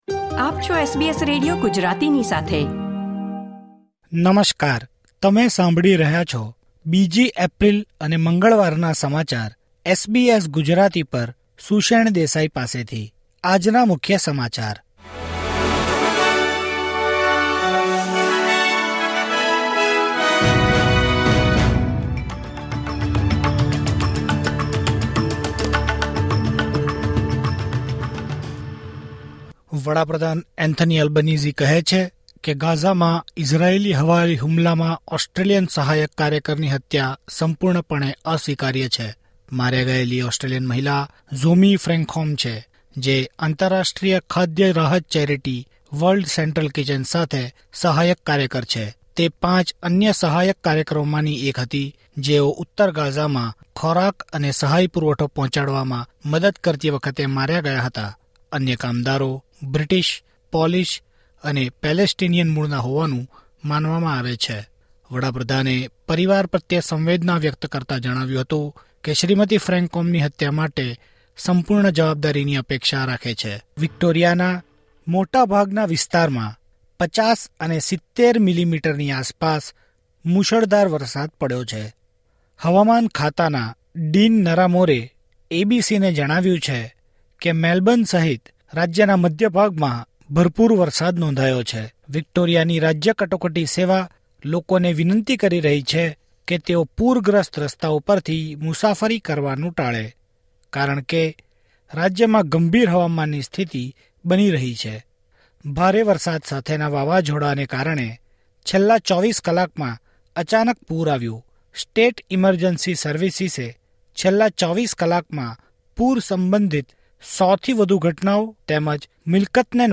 SBS Gujarati News Bulletin 2 April 2024